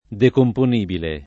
[ dekompon & bile ]